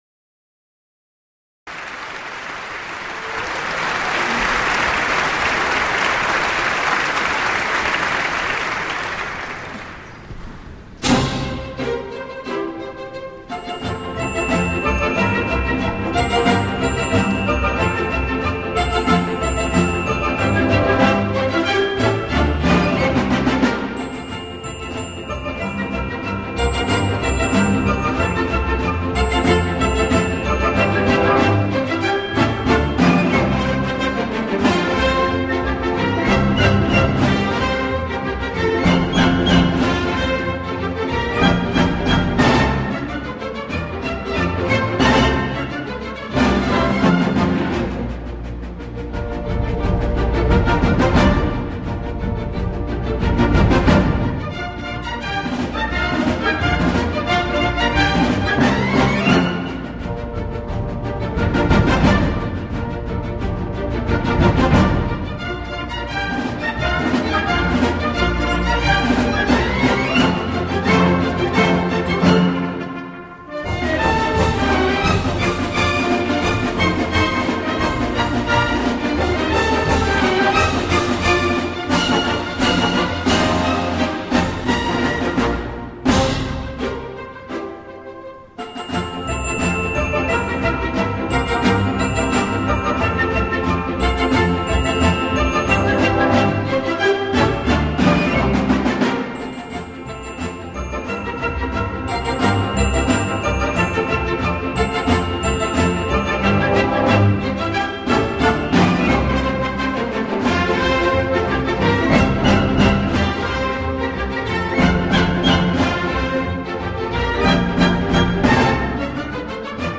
他的指挥下的音乐，音色华丽柔美，既能展现出铜管乐器的嘹亮高亢，又能展现出弦乐器的优雅醇厚。
快速波尔卡